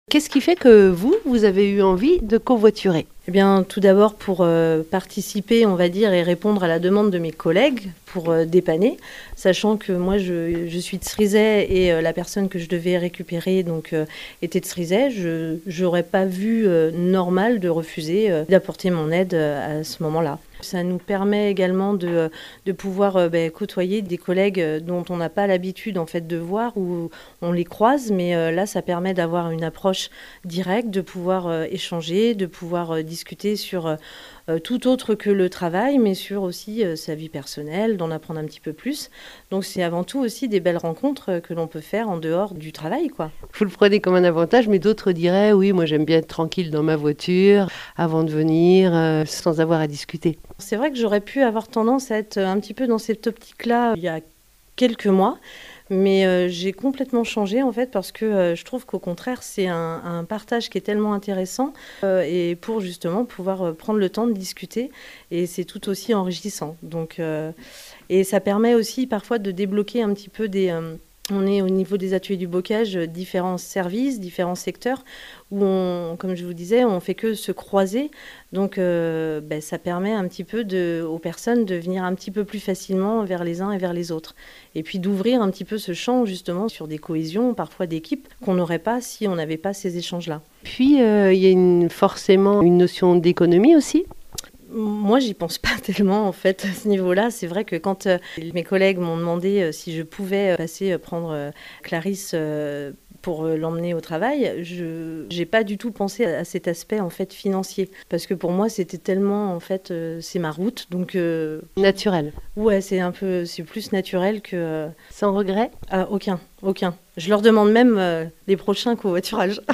Des témoignages de celles et ceux qui, pour aller travailler, vivent la Mobilité en Partage à l’initiative des Ateliers du Bocage ! 0:00 2 min